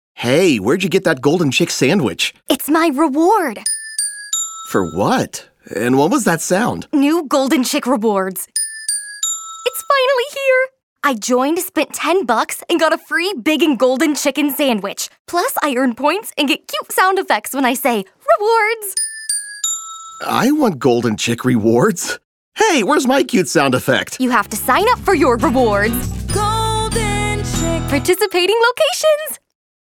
Golden Chick Radio Spot